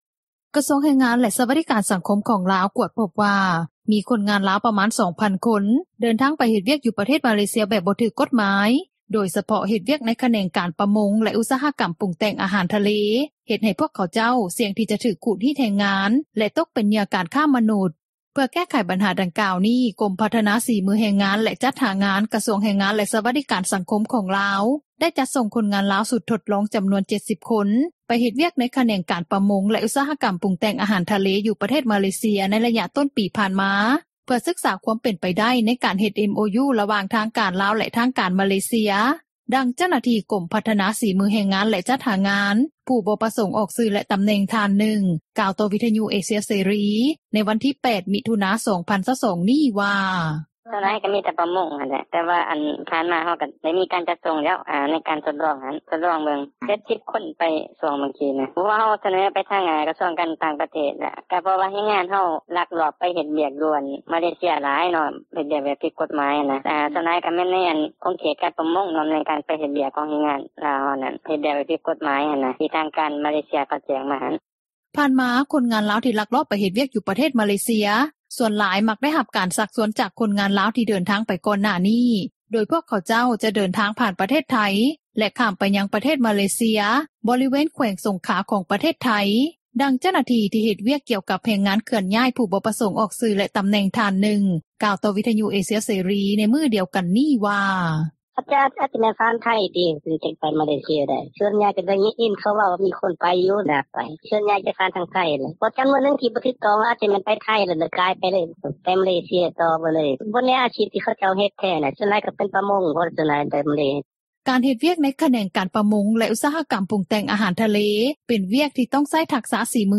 ດັ່ງເຈົ້າໜ້າທີ່ ກົມພັທນາສີມືແຮງງານ ແລະຈັດຫາງານ ຜູ້ບໍ່ປະສົງອອກຊື່ແລະຕໍາແໜ່ງ ທ່ານນຶ່ງ ກ່າວຕໍ່ວິທຍຸເອເຊັຽເສຣີ ໃນວັນທີ່ 8 ມິຖຸນາ 2022 ນີ້ວ່າ:
ດັ່ງນັກສິດທິມະນຸດ ທີ່ເຮັດວຽກດ້ານແຮງງານຂ້າມຊາດ ນາງນຶ່ງ ກ່າວວ່າ: